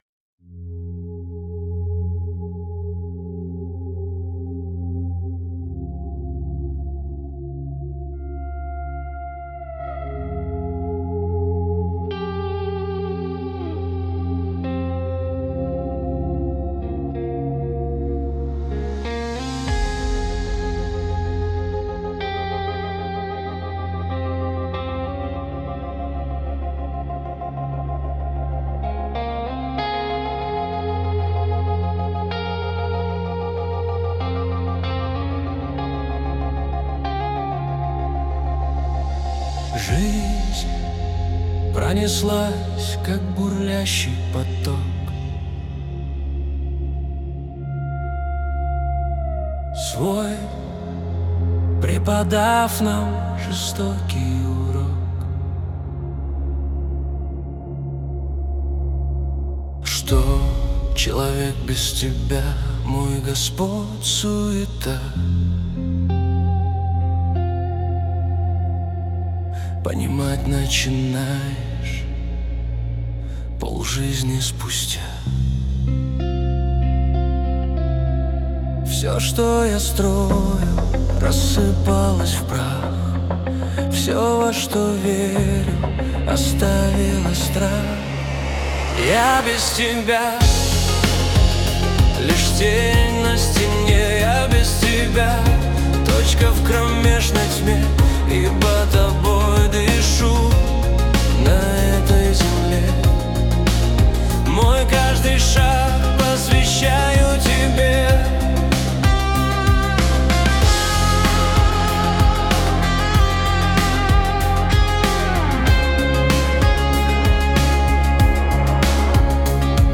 песня ai
162 просмотра 693 прослушивания 77 скачиваний BPM: 95